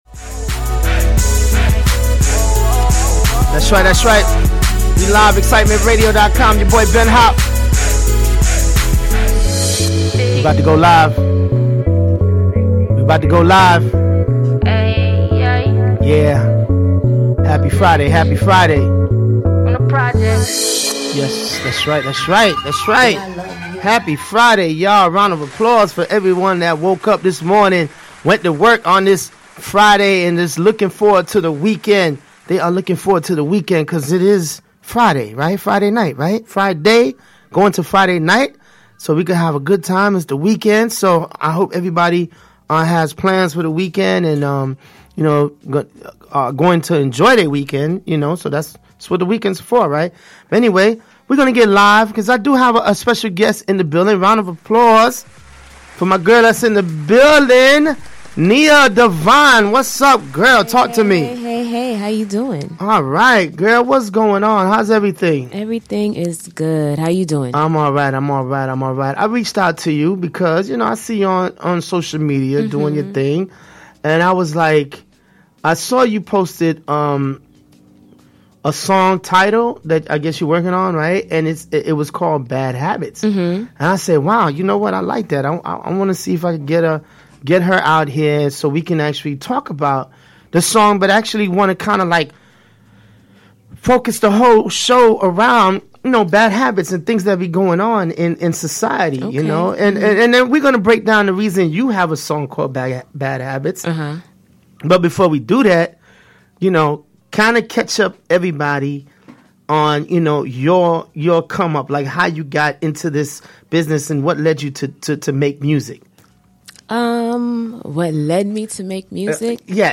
Showcasing Independent Artist from all over the world.